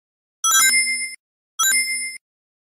Звук кільця